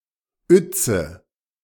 Uetze (German pronunciation: [ˈʏtsə]